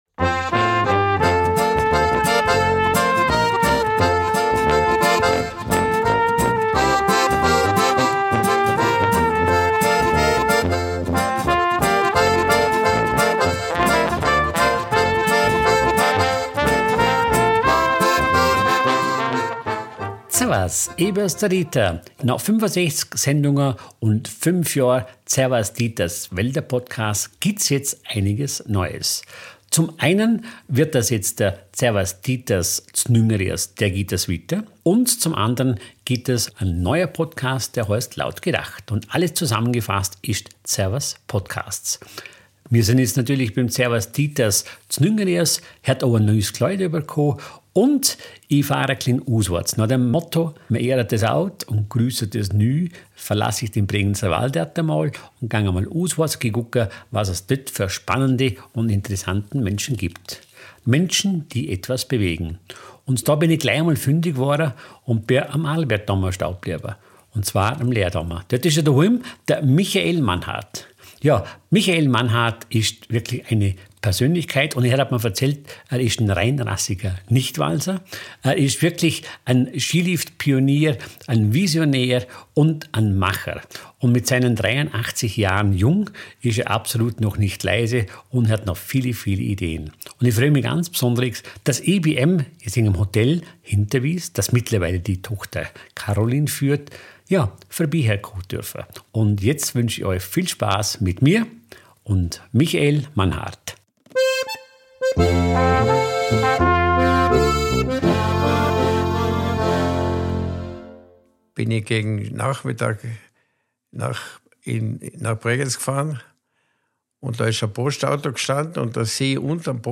Ein Gespräch über Herkunft, Verantwortung, Veränderung – und darüber, warum Stillstand nie eine Option war.